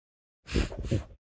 Minecraft Version Minecraft Version snapshot Latest Release | Latest Snapshot snapshot / assets / minecraft / sounds / mob / sniffer / scenting1.ogg Compare With Compare With Latest Release | Latest Snapshot
scenting1.ogg